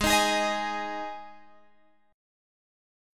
G#5 chord {16 18 18 x 16 16} chord